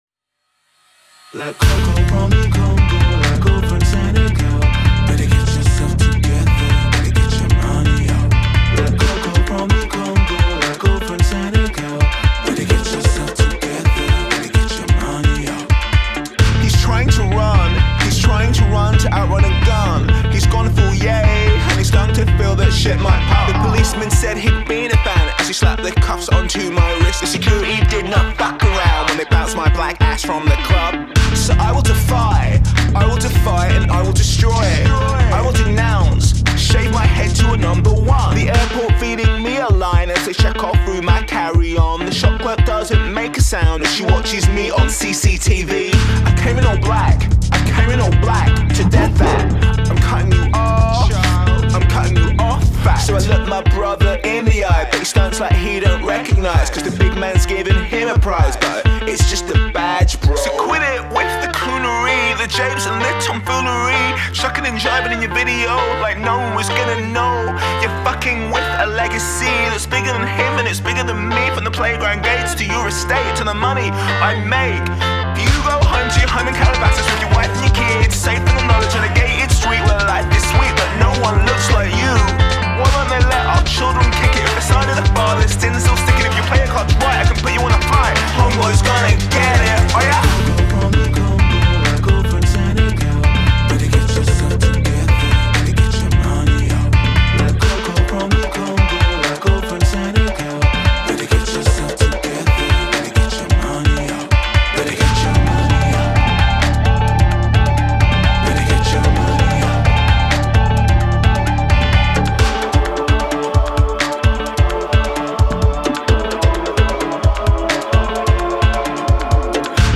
Eclecticism is the aim of the game…